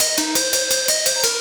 Index of /musicradar/shimmer-and-sparkle-samples/170bpm
SaS_Arp01_170-C.wav